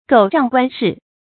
狗仗官勢 注音： ㄍㄡˇ ㄓㄤˋ ㄍㄨㄢ ㄕㄧˋ 讀音讀法： 意思解釋： 比喻壞人倚仗官府勢力欺壓他人。